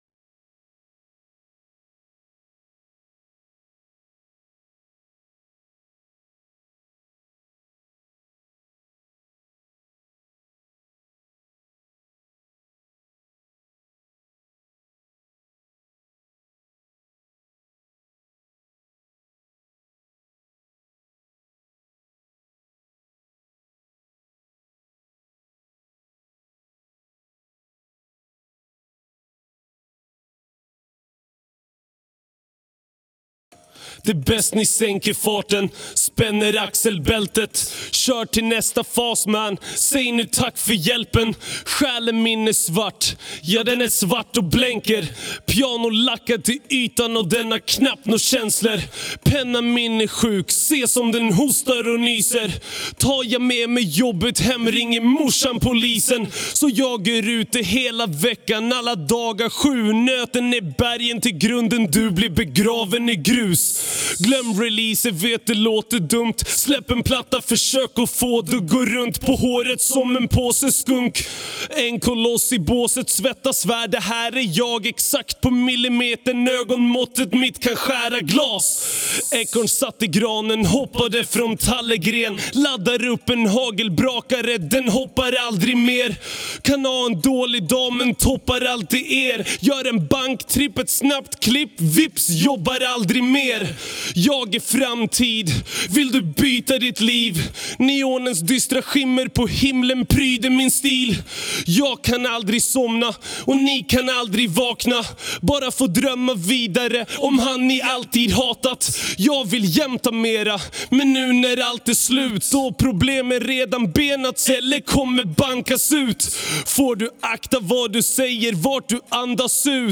1. Ladda ner acapellan